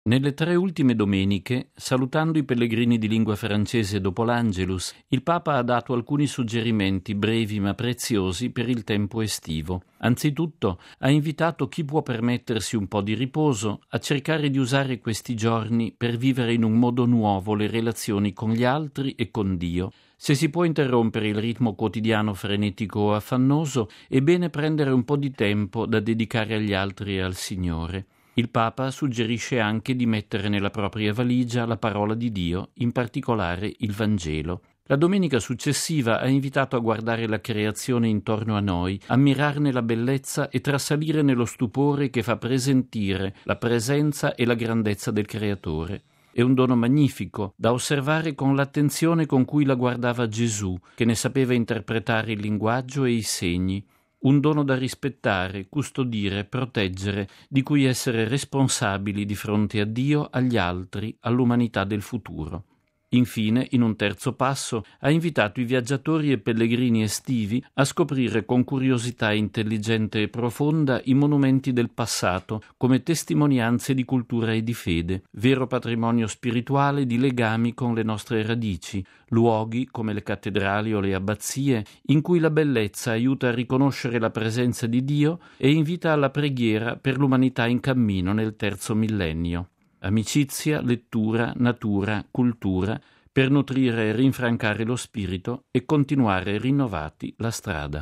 Come riposare? Editoriale